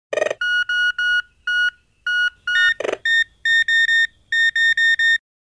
Metal-detector-found-object-beeps.mp3